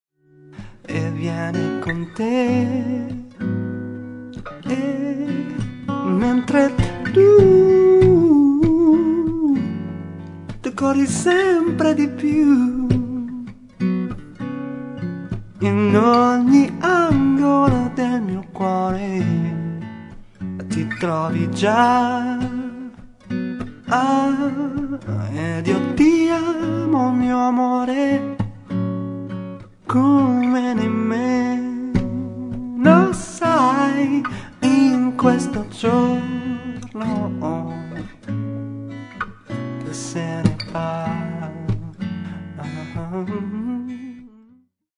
abbiamo montato un paio di microfoni
registrato e missato a 'la oficina' studio (bologna)